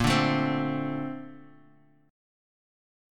BbM9 Chord
Listen to BbM9 strummed